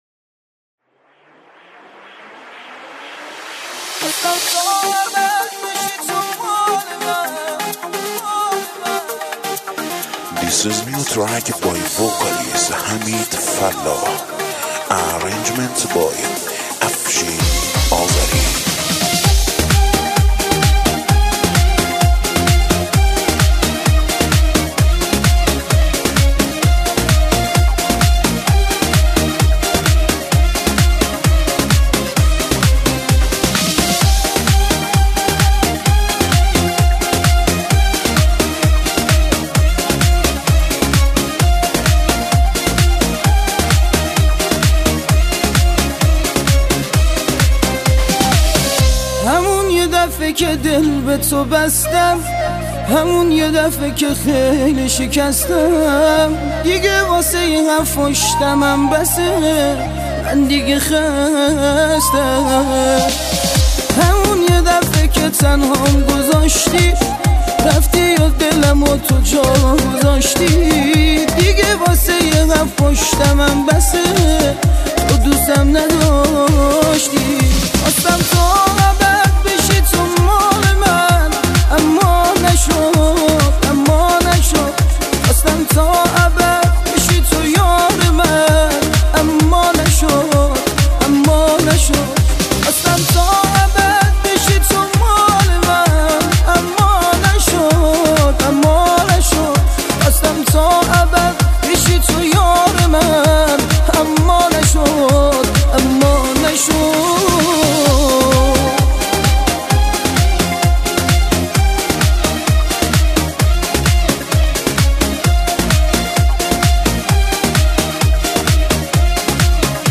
پاپ
آهنگ لاتی